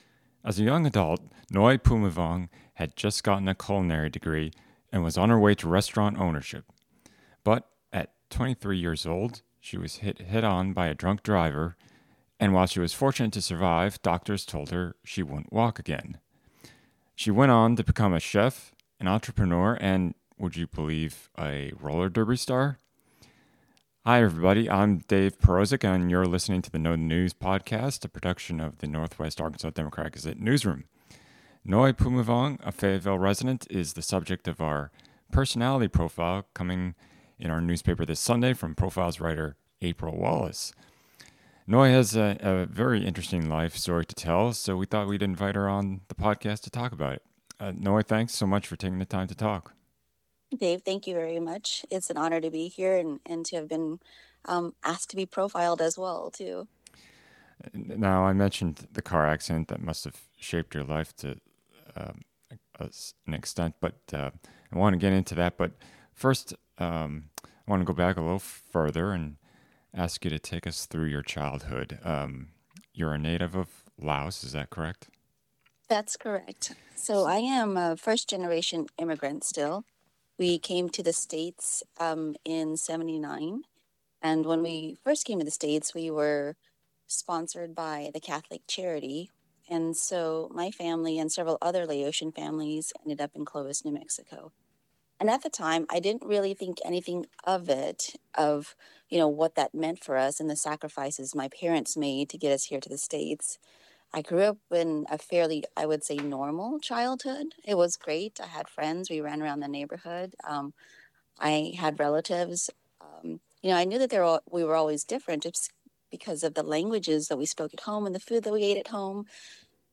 Know the News - Interview